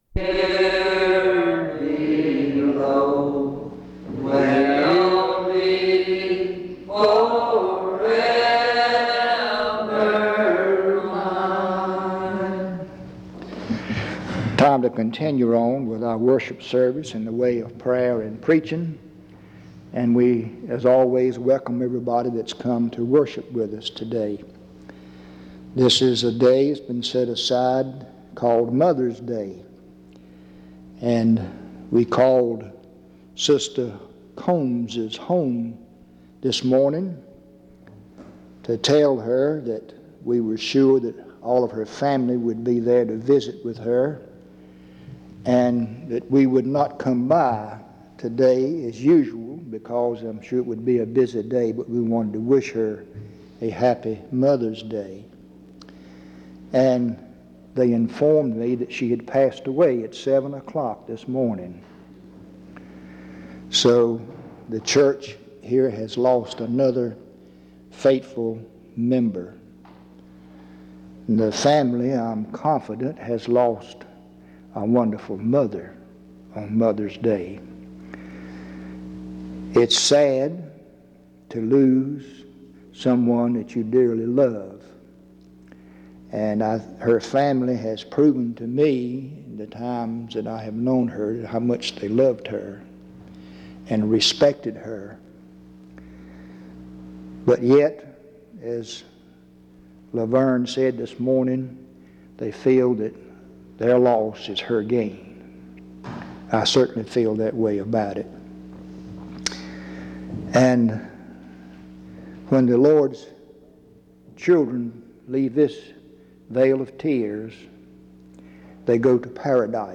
En Collection: Reidsville/Lindsey Street Primitive Baptist Church audio recordings Miniatura Título Fecha de subida Visibilidad Acciones PBHLA-ACC.001_005-A-01.wav 2026-02-12 Descargar PBHLA-ACC.001_005-B-01.wav 2026-02-12 Descargar